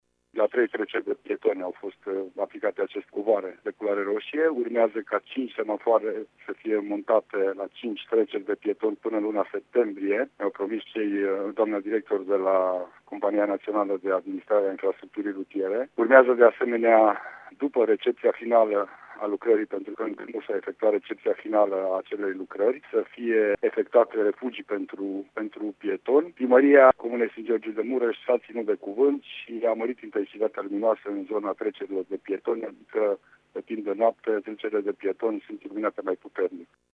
Ce alte măsuri au fost implementate pană acum ne spune prefectul Lucian Goga: